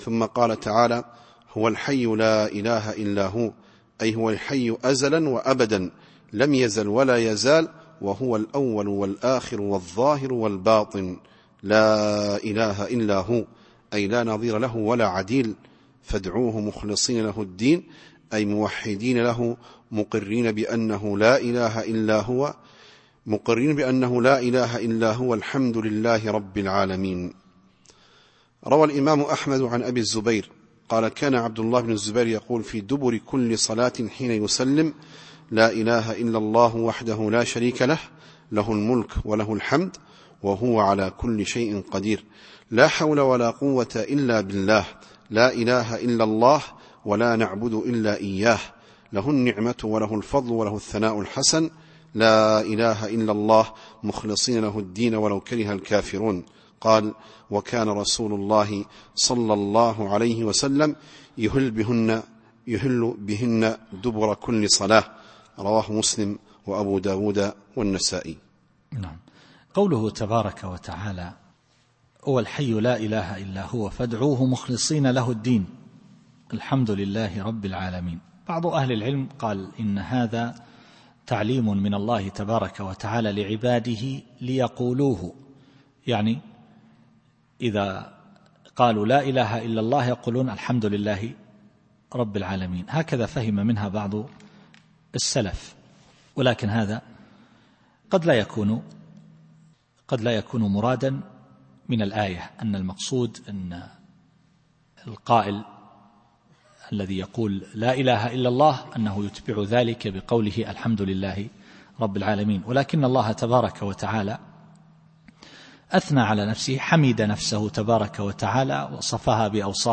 التفسير الصوتي [غافر / 65]